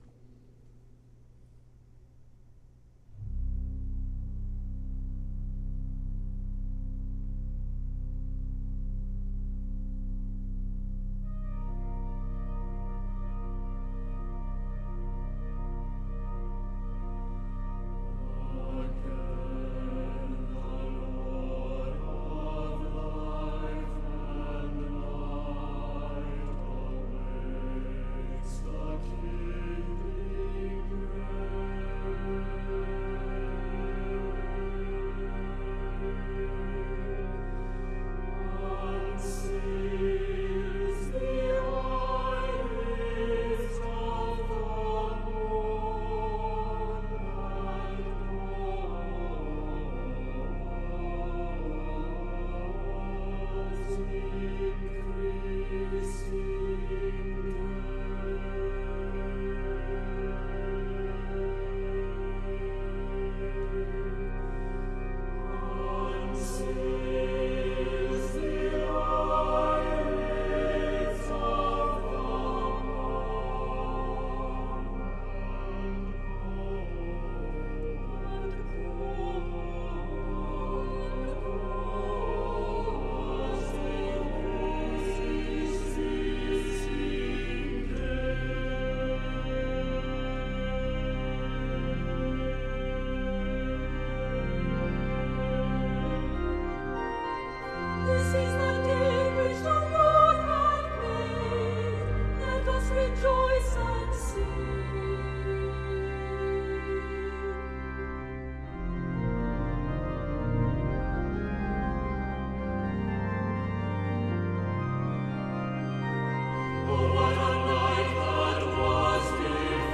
The latter returns as a refrain for children's voices.